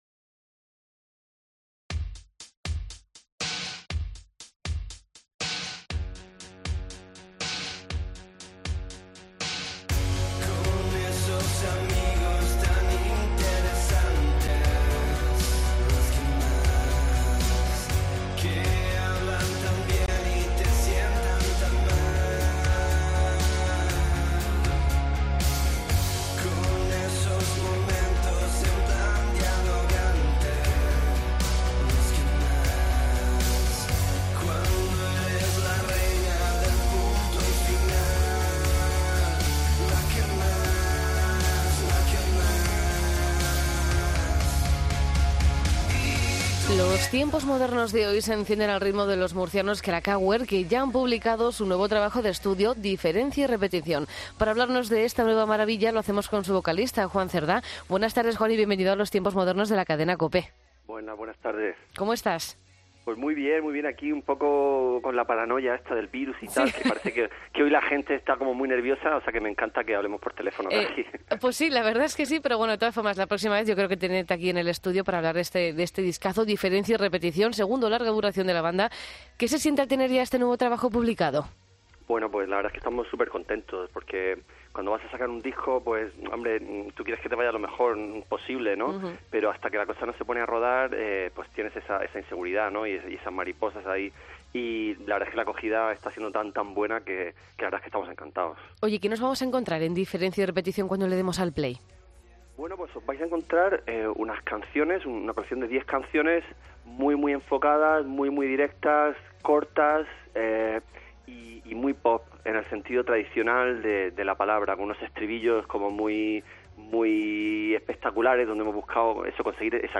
Entrevista a Kracauer en los Tiempos Modernos